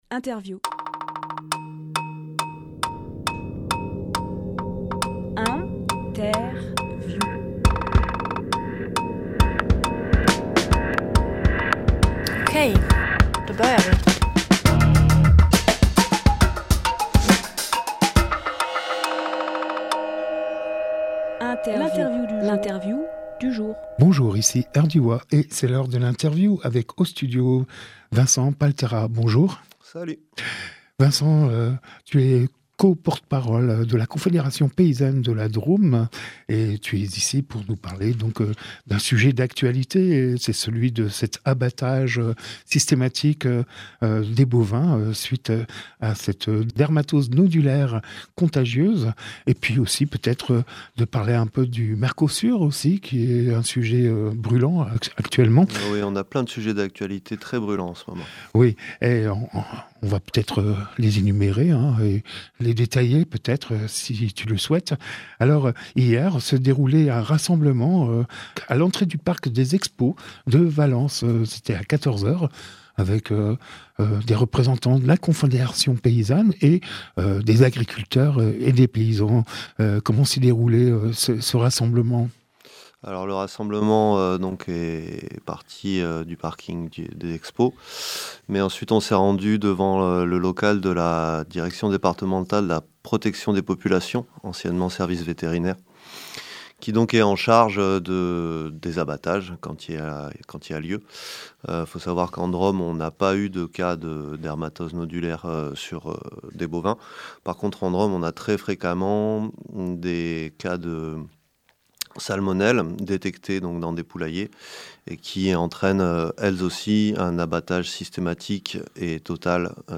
Emission - Interview La confédération paysanne de la Drôme sur les épizooties Publié le 24 décembre 2025 Partager sur…
lieu : Studio RDWA